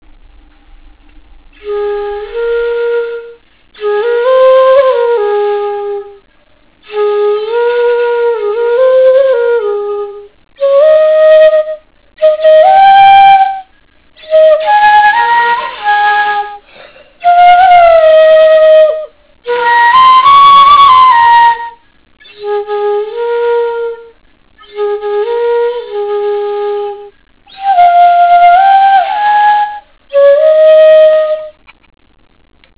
חליל צד אירי
י"ט בתשרי תשס"ט, 23:29 ניגון חליל תמיד נעים לאוזן י אנונימי י ז' בשבט תשס"ט, 17:06 קצת צורם..